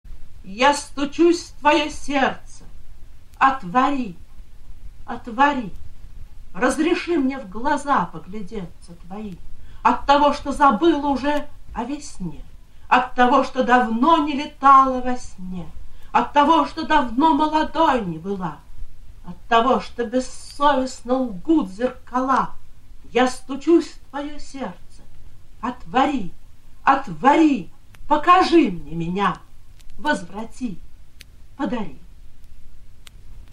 Вероника Тушнова – Я стучусь в твое сердце (читает автор)